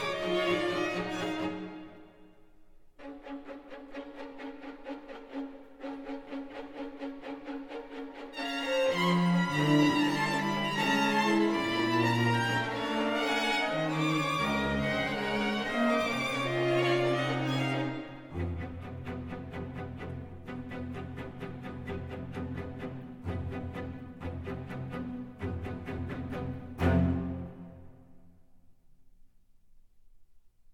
"enPreferredTerm" => "Musique vocale sacrée"